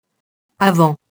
avant [avɑ̃] nom masculin